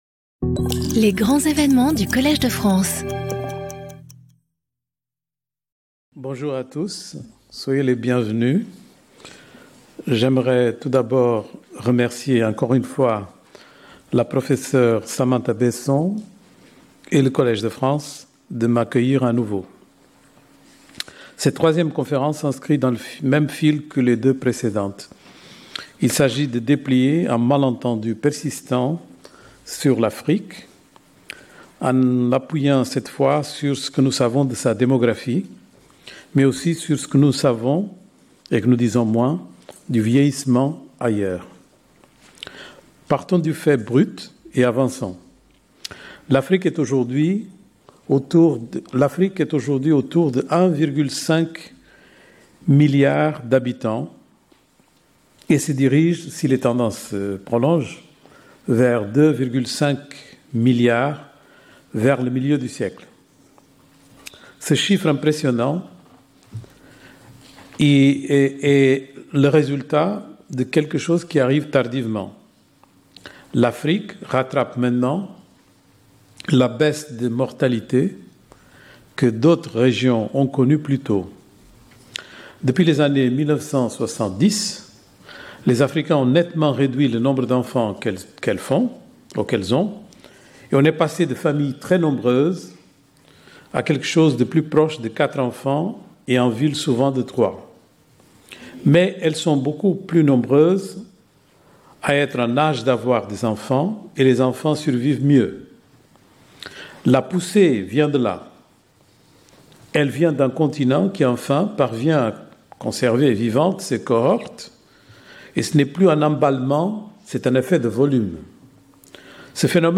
Guest lecturer